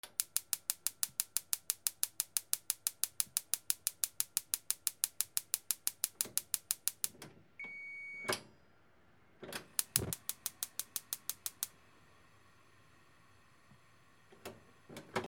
ガスコンロ 点火